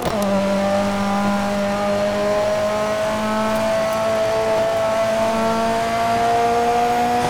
Index of /server/sound/vehicles/lwcars/delta
4cruise.wav